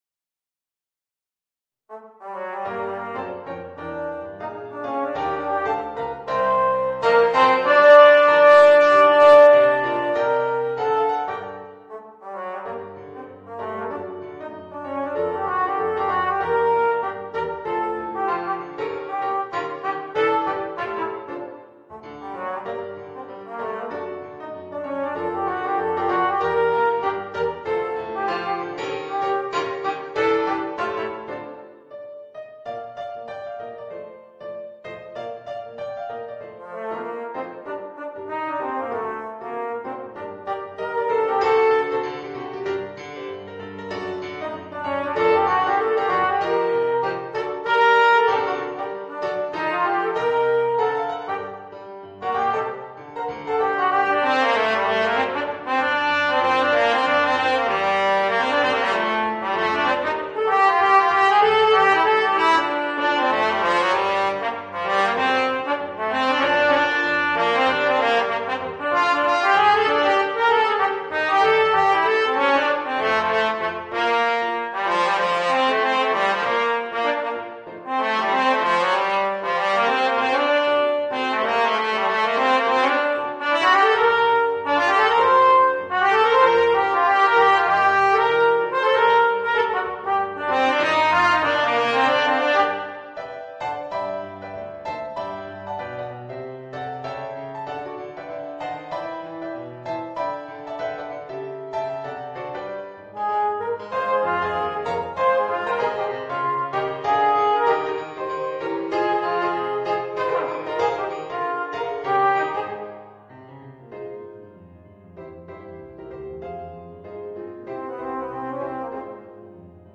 Voicing: Alto Trombone and Piano